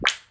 clap4.mp3